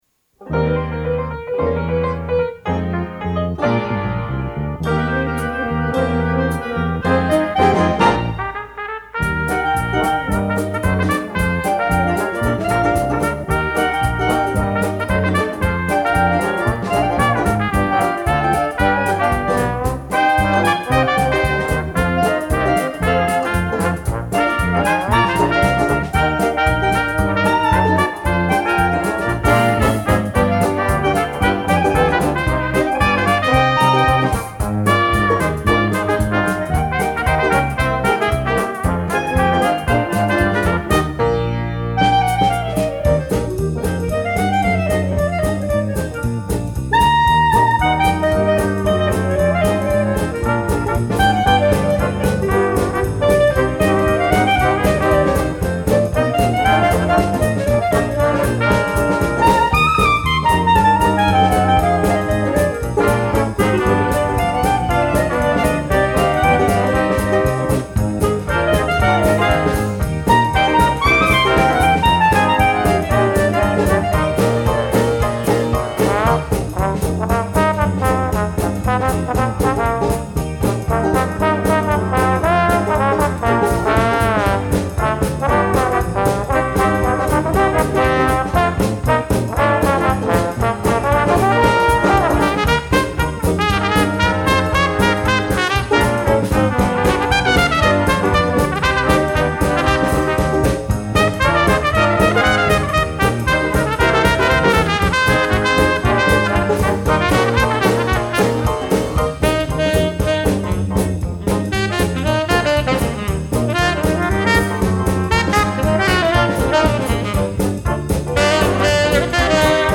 Gattung: Dixieland Combo
Besetzung: Dixieland Combo